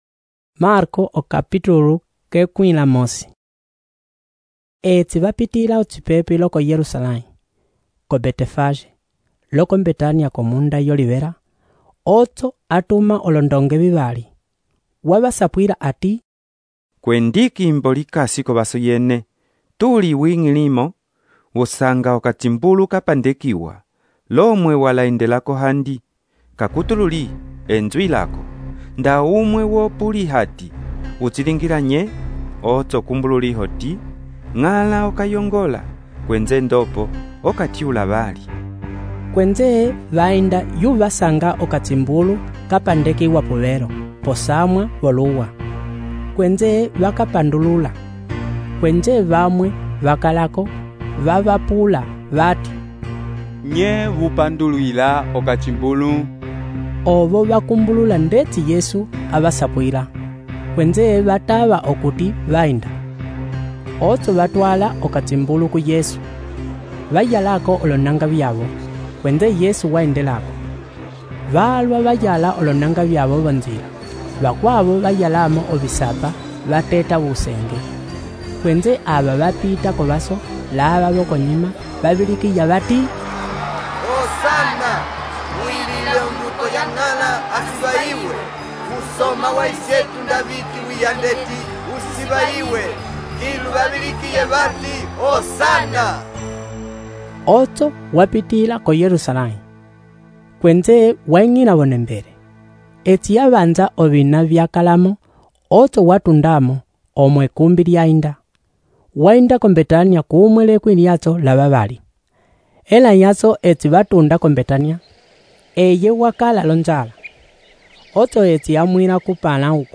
texto e narração , Marko, Okapitulu 11 - A entrada triunfal de Jesus em Jerusalém.